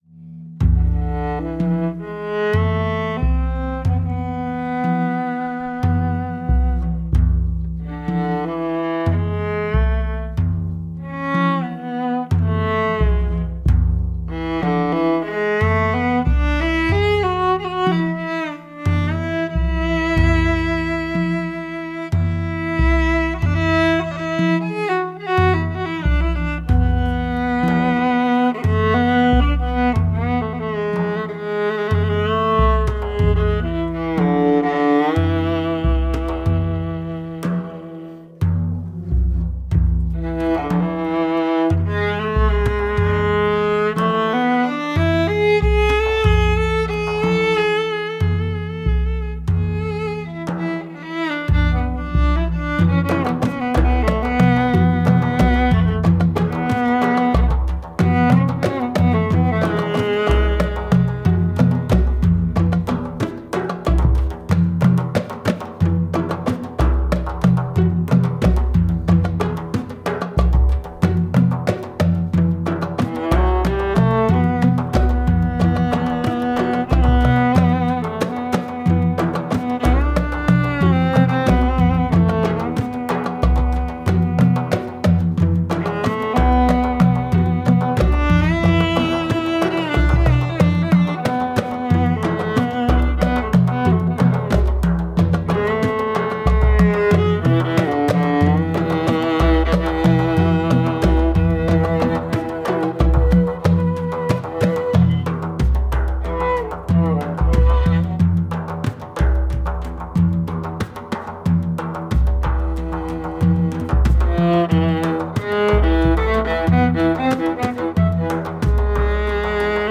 Viola
Percussion